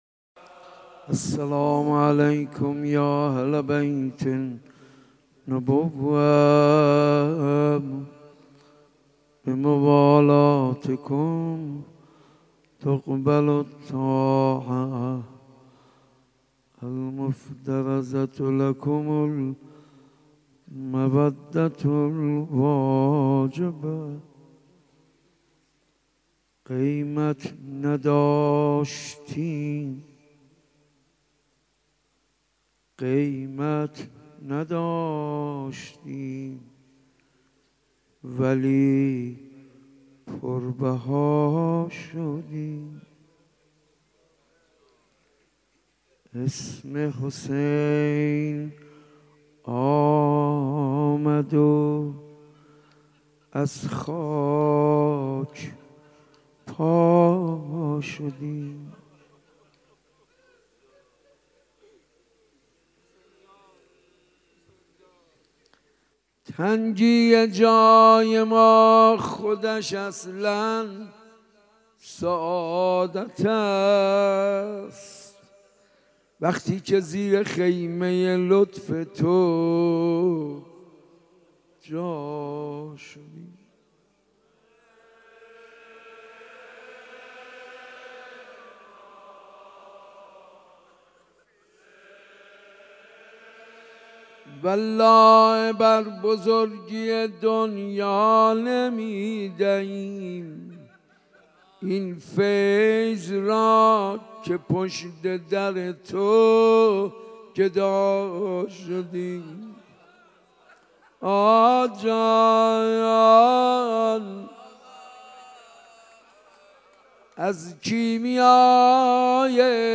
مداحی
صوت مراسم شب چهارم محرم ۱۴۳۷ مسجد ارگ ذیلاً می‌آید: .:اشکال در بارگذاری پخش کننده:.